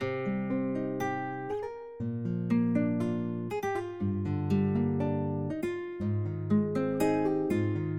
门打开和关闭 " 卧室门关闭
描述：卧室门关闭